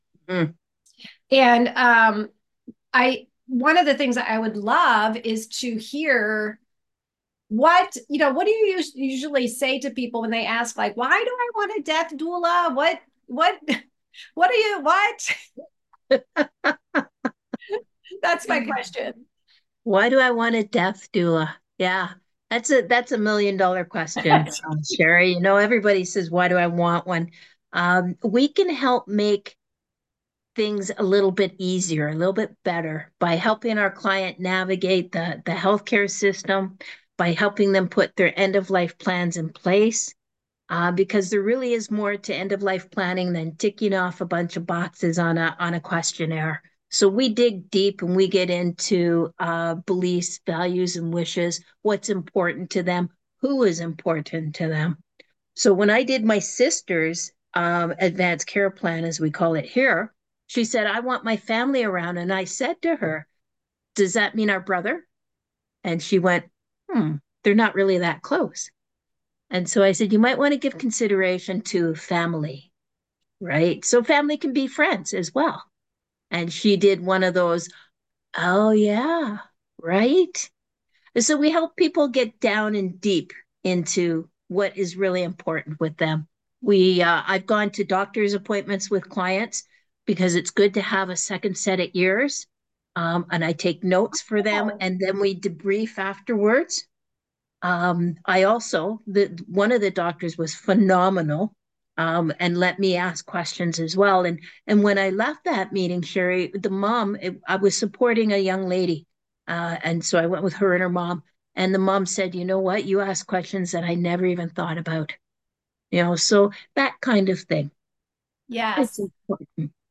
Death Doula Conversation
I promised you I’d share a deeper conversation with a death doula.
Death-Doula-Conversation.m4a